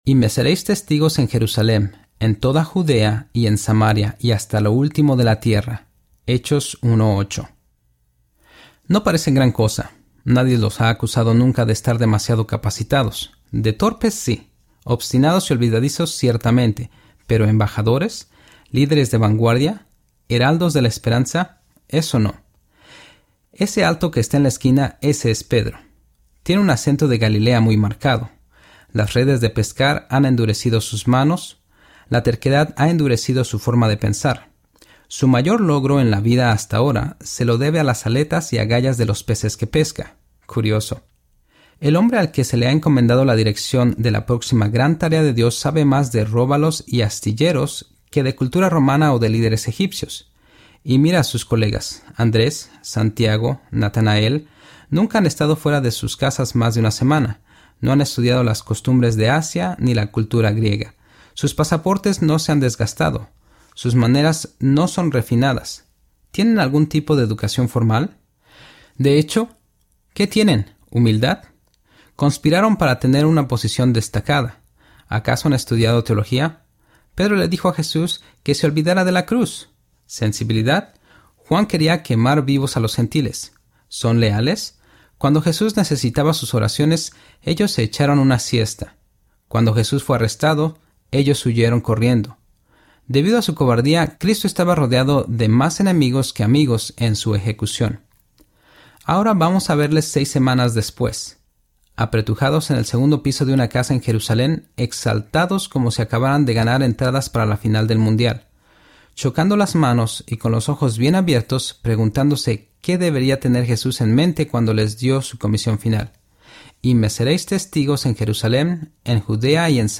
Mas Alla de Tu Vida Audiobook
4.0 Hrs. – Unabridged